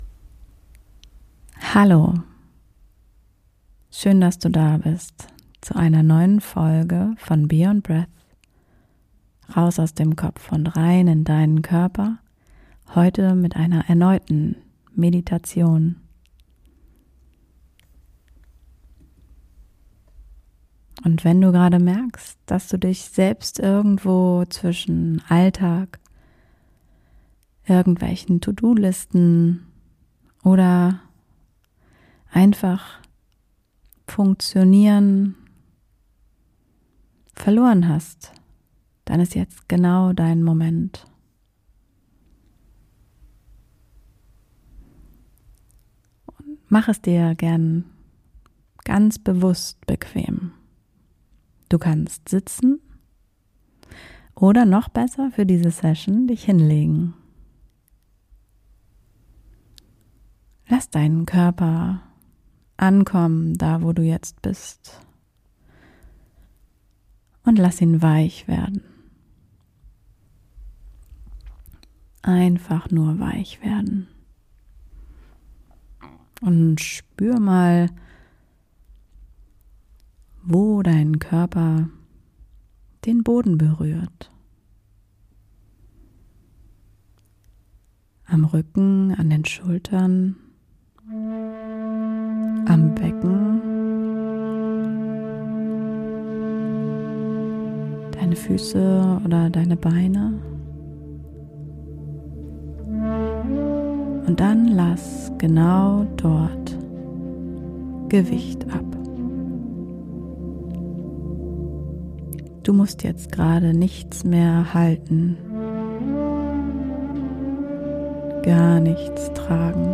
Eine geführte Meditation für dich, wenn du merkst, dass du im Alltag nur noch funktionierst und dich selbst irgendwo verloren hast. Über deinen Atem, deinen Herzraum und eine bewusste Körperwahrnehmung kommst du wieder bei dir an.